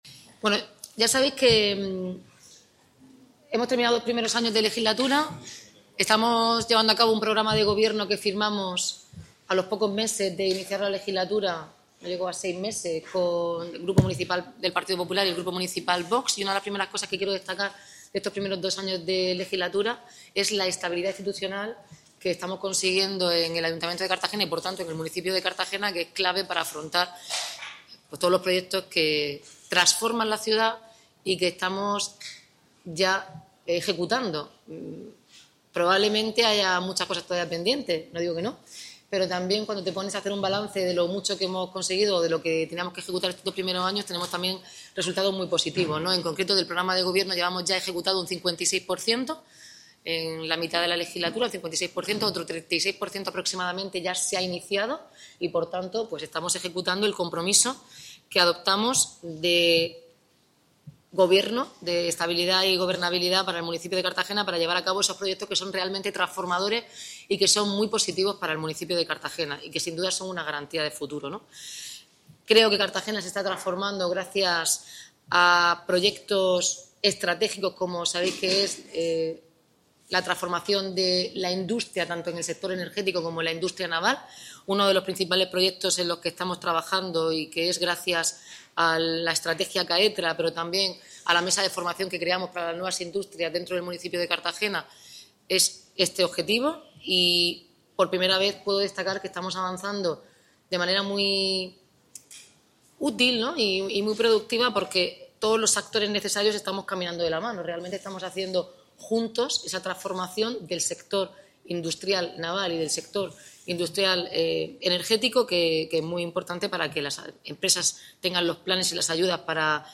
Enlace a Declaraciones de la alcaldesa, Noelia Arroyo.
El anuncio lo ha hecho Noelia Arroyo este viernes en el transcurso del desayuno informativo que ha mantenido con los medios de comunicación coincidiendo con el ecuador de su mandato en el ayuntamiento